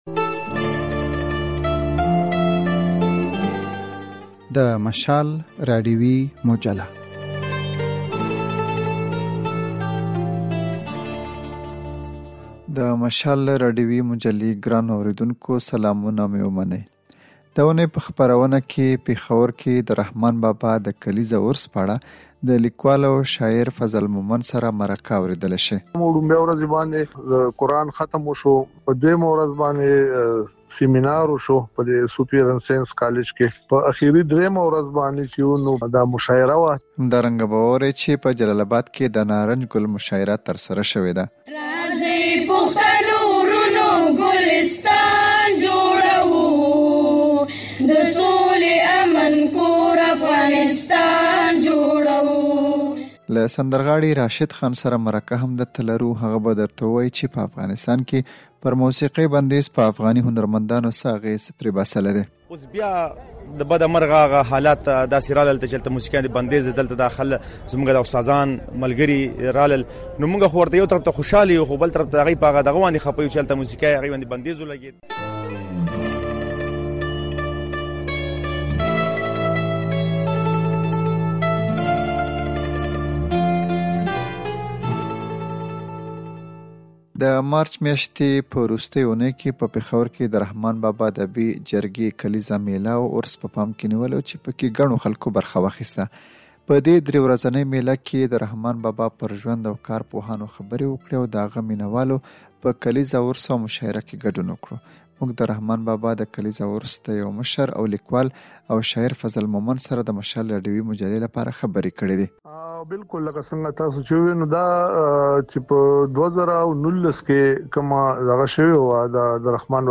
دا اوونۍ په مشال راډیويي مجله کې په پېښور کې د رحمان بابا کلیزه عرس پر مهال د مشاعرې په اړه مرکه، په جلال اباد کې د نارنج ګل کلیزه مشاعرې په اړه راپور